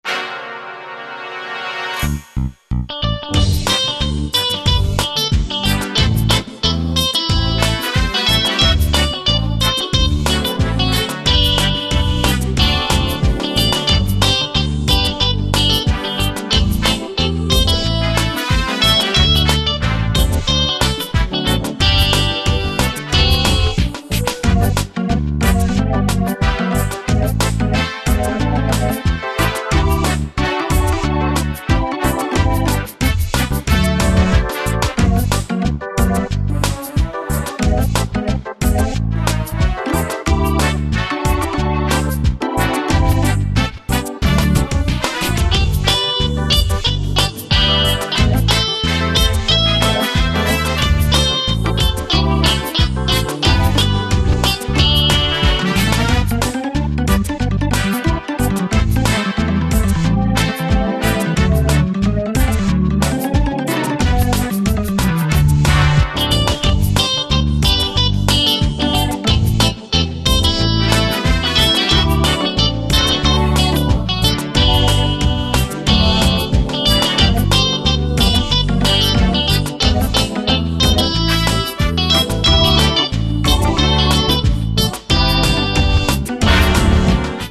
Веселая музычка, типа для мультфильма.